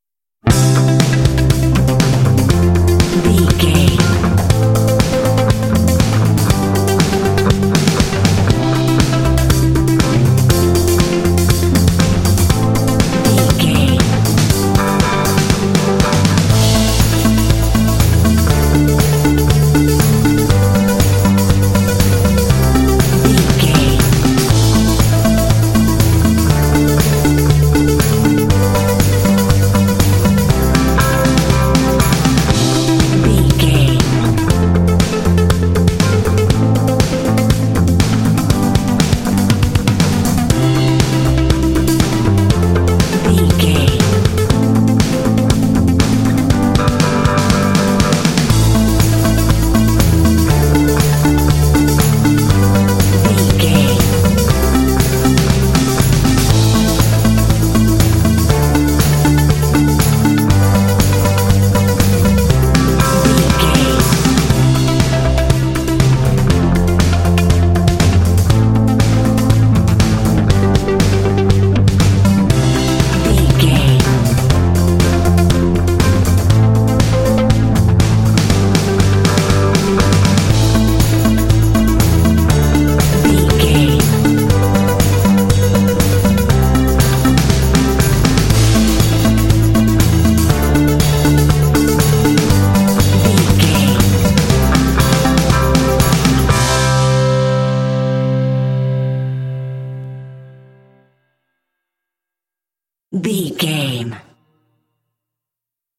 Uplifting
Ionian/Major
driving
happy
cool
percussion
drums
bass guitar
synthesiser
electric guitar
rock
pop
alternative rock
indie